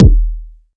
KICK.69.NEPT.wav